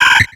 Cri de Tylton dans Pokémon X et Y.